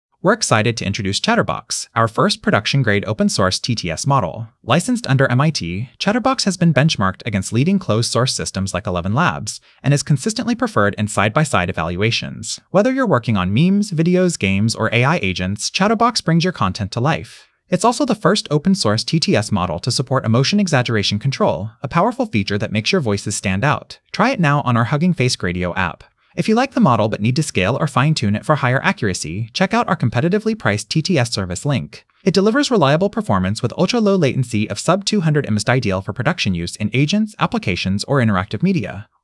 audio-watermarking text-to-speech voice-cloning
Generate expressive, natural speech.
"exaggeration": 0.5